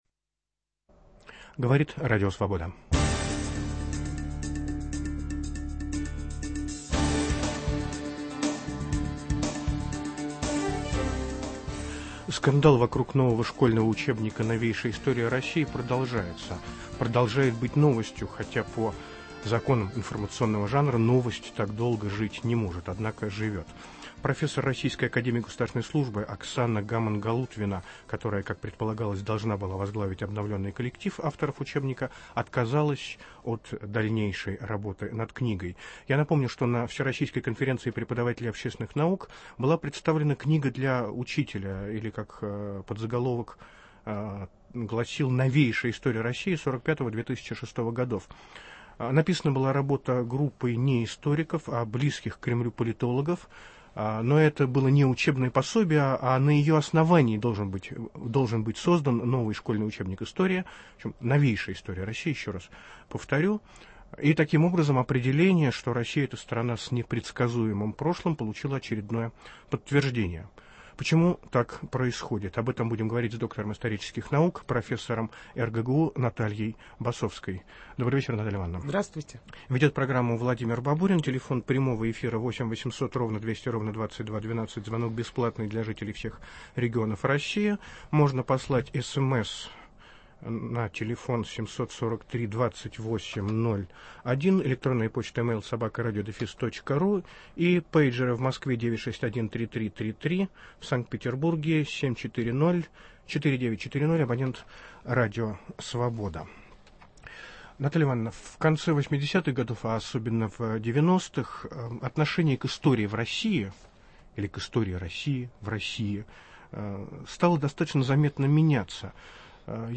В программу приглашена доктор исторических наук, профессор РГГУ Наталья Басовская